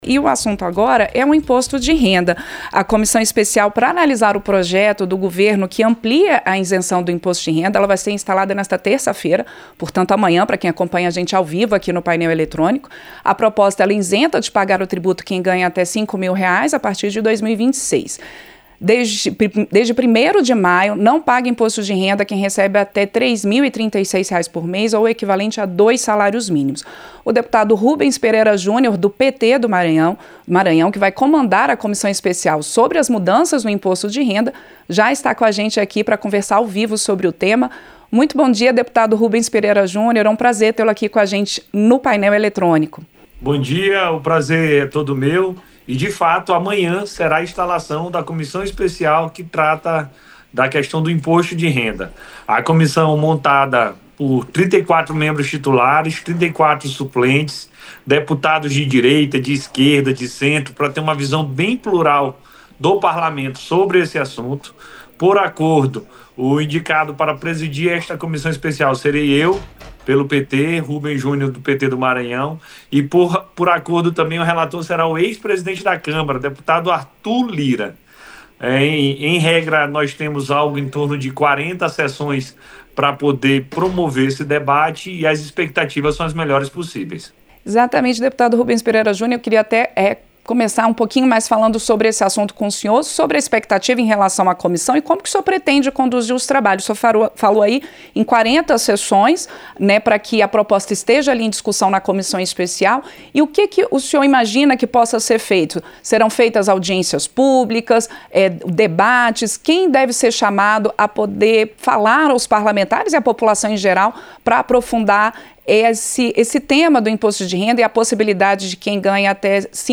Entrevista - Dep. Rubens Pereira Jr (PT-MA)